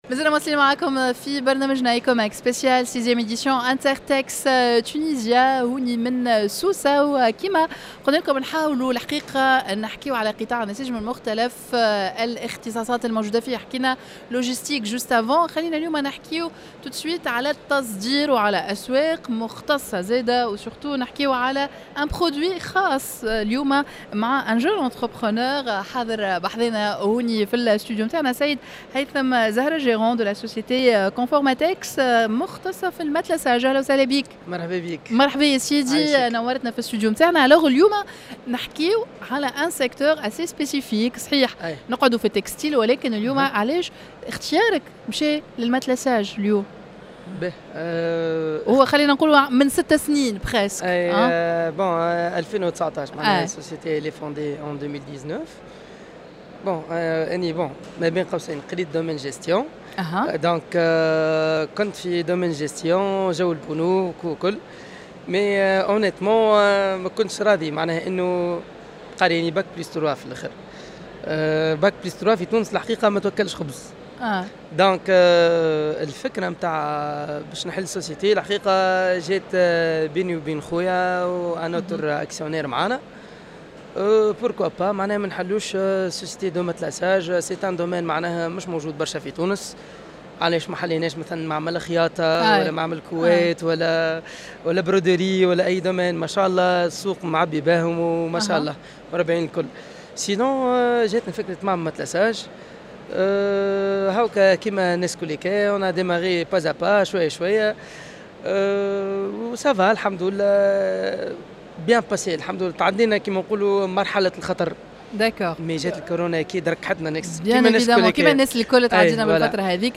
INTERTEX Tunisia dans un plateau spécial en direct de la foire internationale de Sousse.